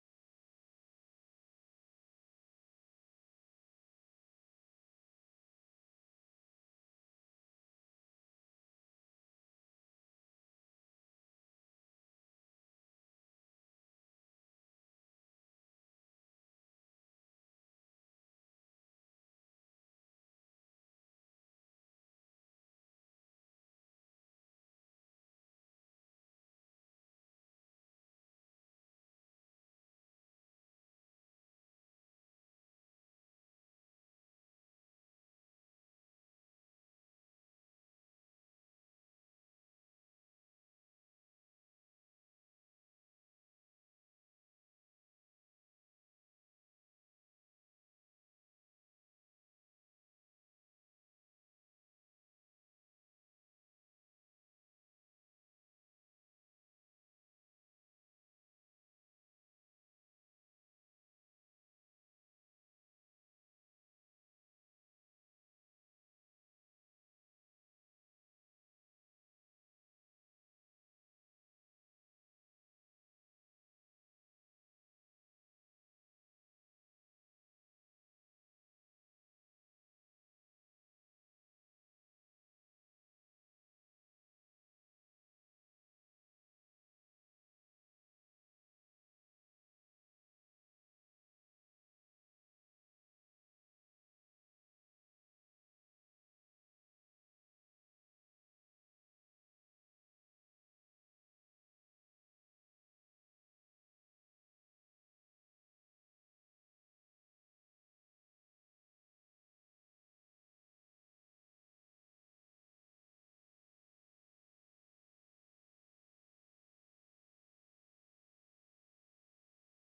A message from the series "Blood of the Martyrs."